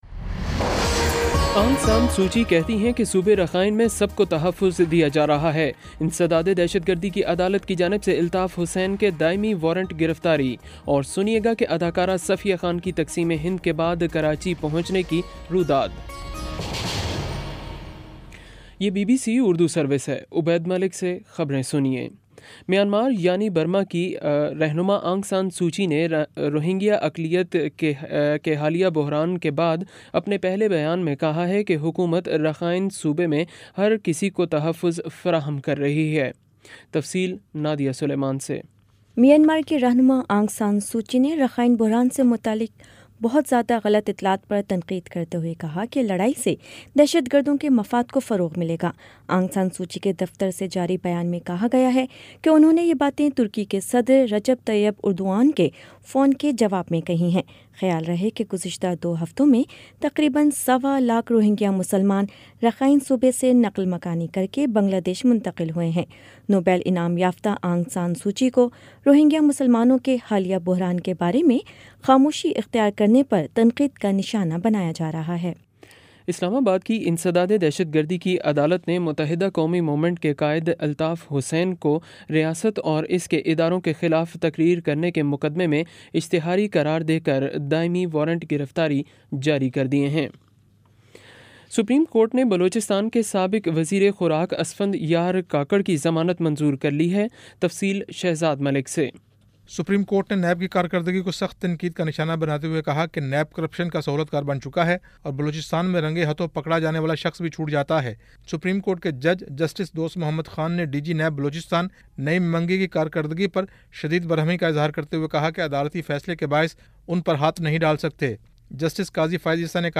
ستمبر 06 : شام چھ بجے کا نیوز بُلیٹن